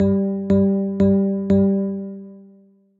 Index of /phonetones/unzipped/BlackBerry/Priv/notifications